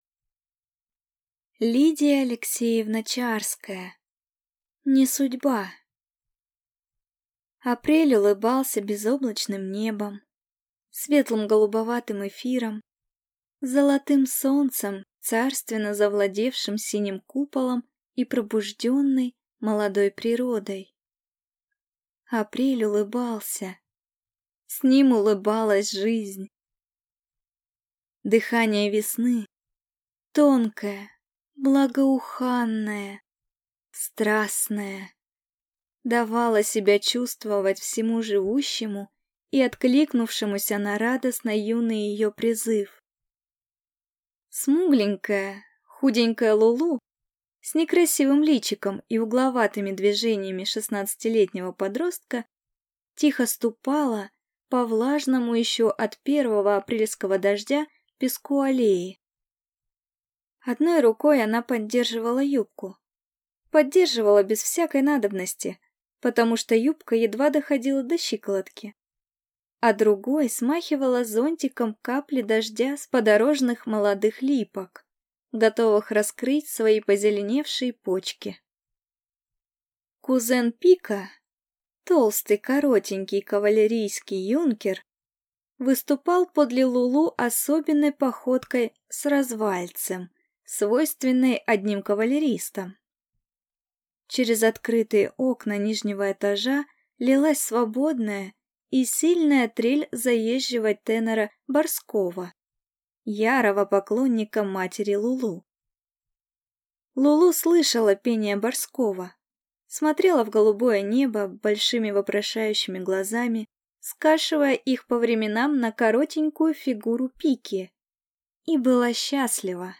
Аудиокнига Не судьба | Библиотека аудиокниг
Прослушать и бесплатно скачать фрагмент аудиокниги